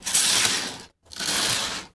Curtains Close Sound
household
Curtains Close